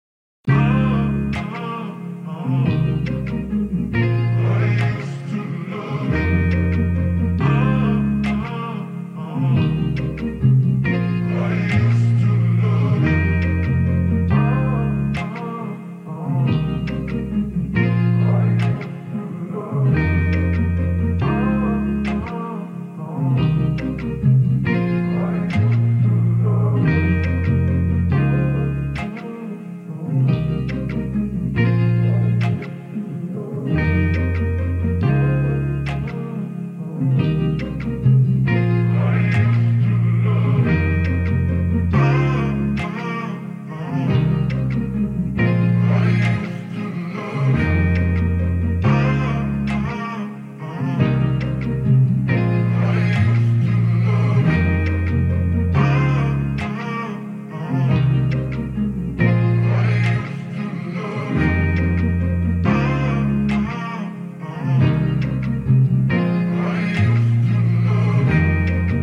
DBM_SC11_139_Music_Loop_I_Used_To_no_bass_Fmin